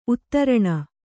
♪ uttaraṇa